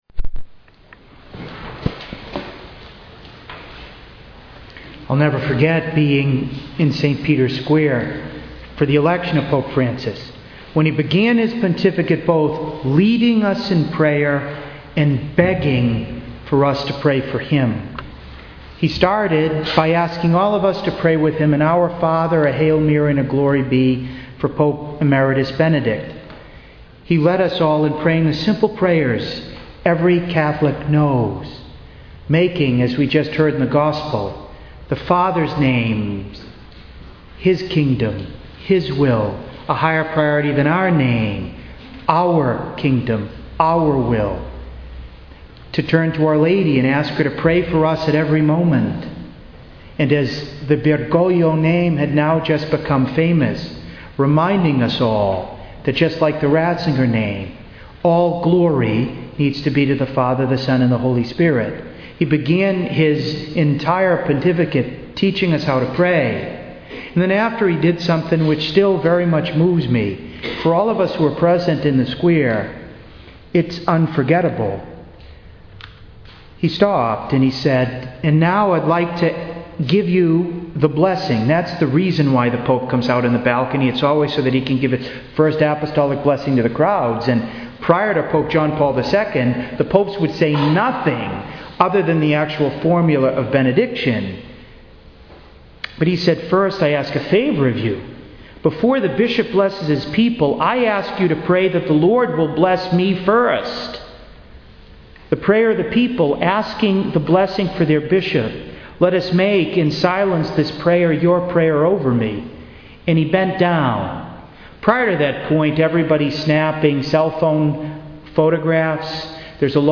To listen to an audio recording of this conference, please click below: